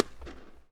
Reverbs from around the world…
Thingvellir_Lögberg_-_Iceland.wav